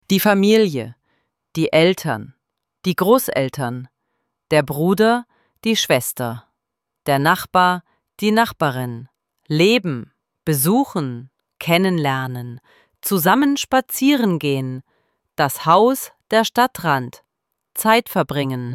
IZGOVOR – RIJEČI I IZRAZI:
ElevenLabs_Text_to_Speech_audio-64.mp3